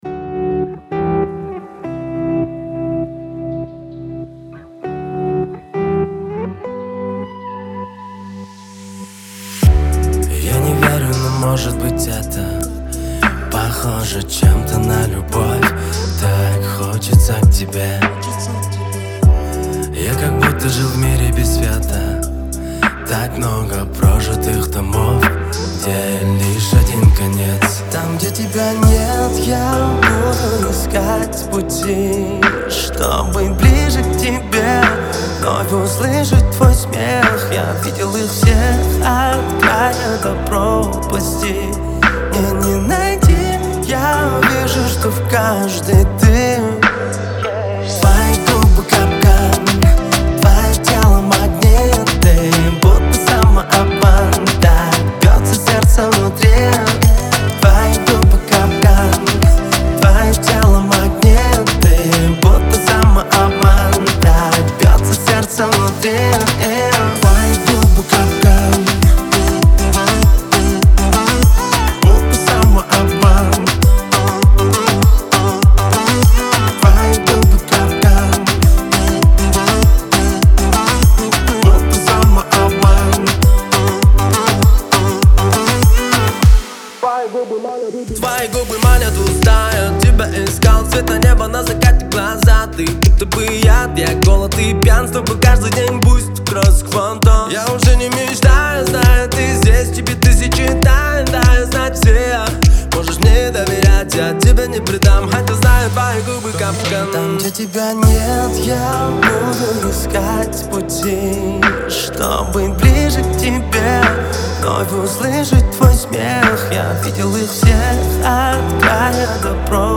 в жанре казахского поп-фолка